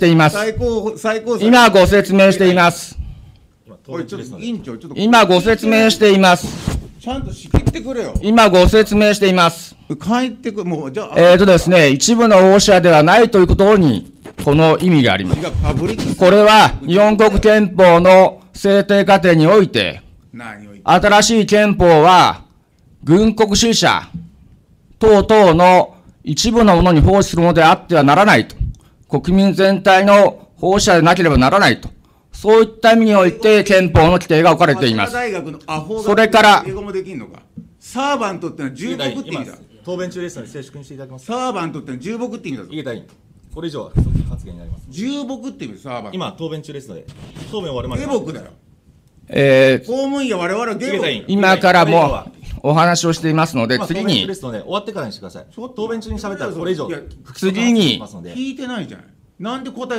資料2　　井桁議員の懲罰動議の契機となった発言　※総務建設委員会 議事録抜粋 令和6年12月9日　（PDF：214KB）
資料2　井桁議員の懲罰動議の契機となった発言　音声　（音声・音楽：747KB）